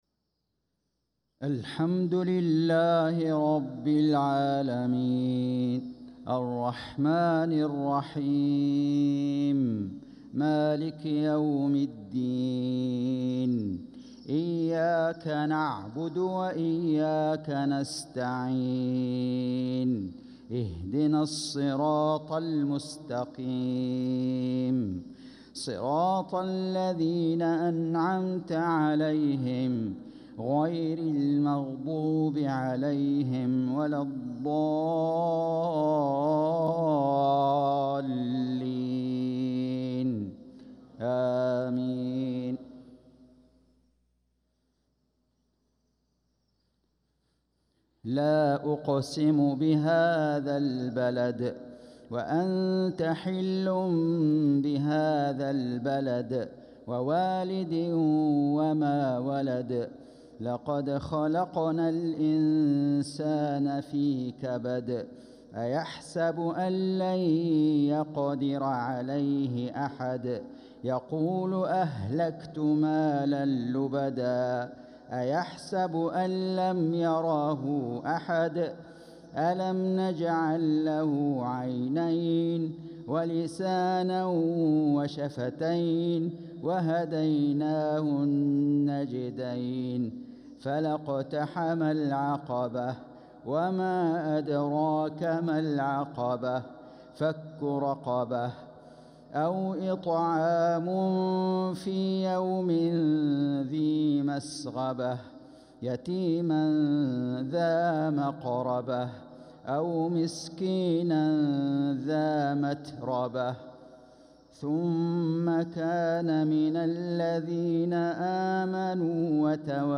صلاة المغرب للقارئ فيصل غزاوي 10 ربيع الآخر 1446 هـ
تِلَاوَات الْحَرَمَيْن .